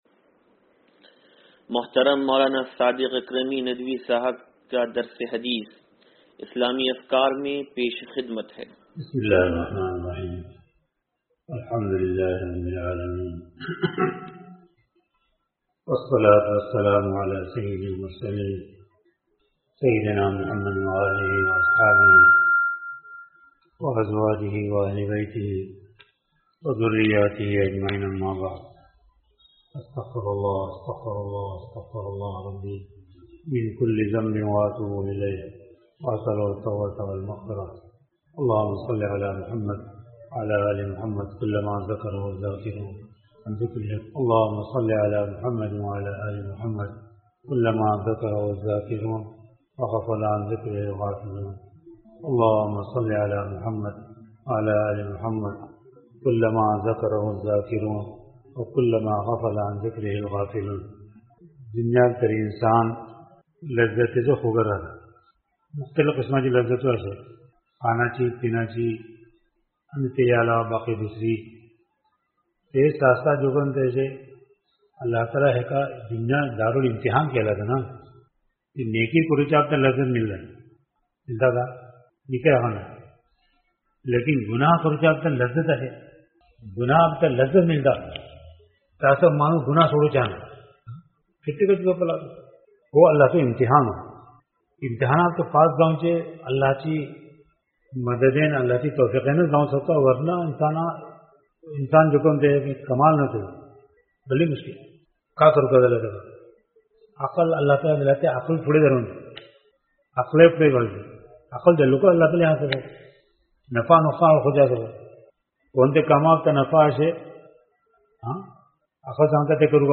درس حدیث نمبر 0486